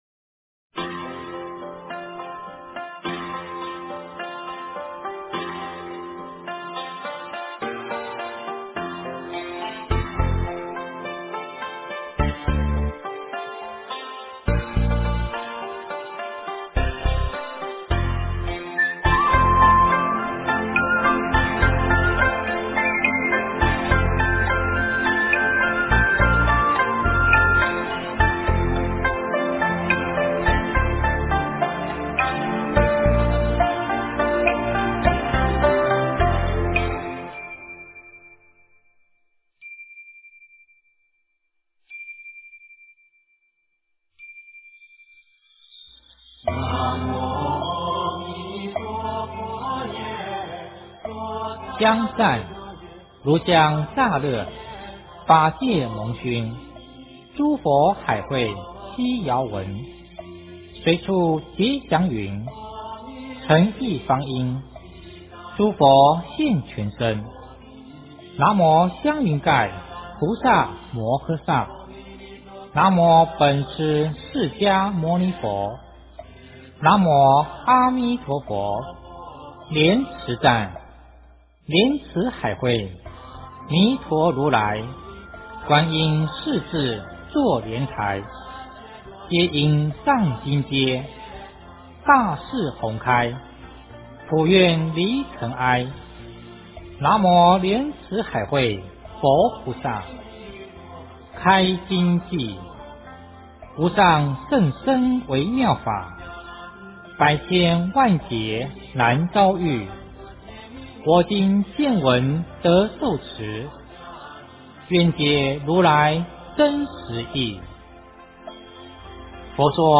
佛说阿弥陀经 - 诵经 - 云佛论坛